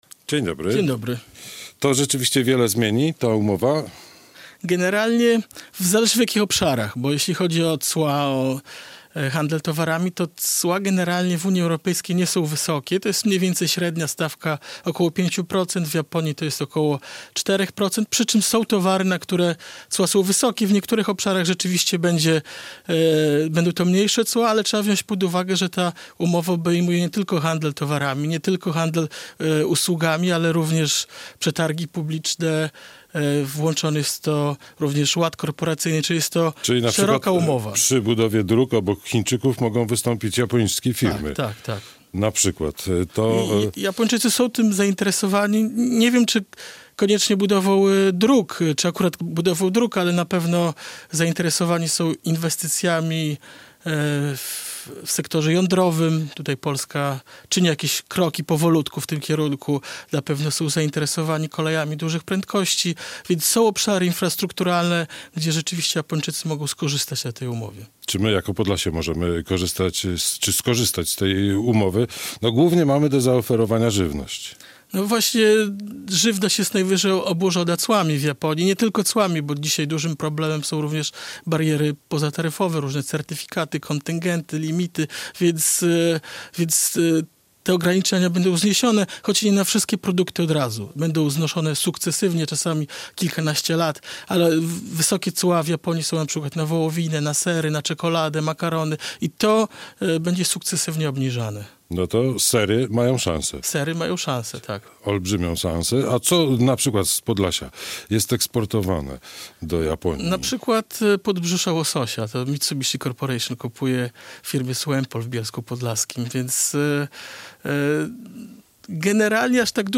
Gość
ekspert od gospodarek azjatyckich